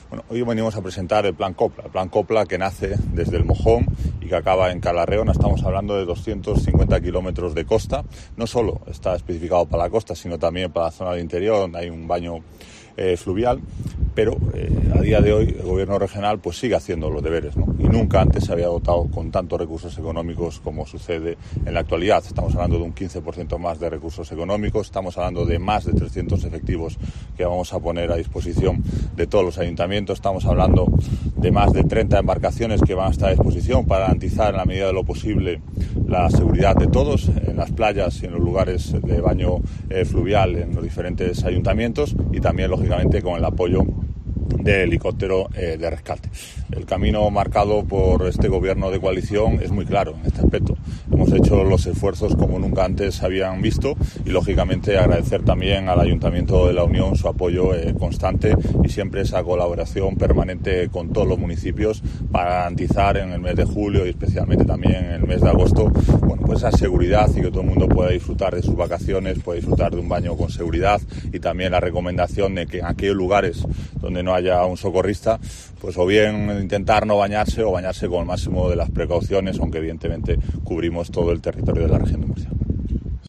Así lo anunció Antelo, en la playa del Lastre, en La Unión, durante la presentación del plan, que se desarrollará los meses de julio y agosto a lo largo de la costa regional, que se extiende desde El Mojón (límite con Alicante) hasta Cala Reona (límite con Almería), con una longitud de 250 kilómetros, 73 de ellos en el Mar Menor.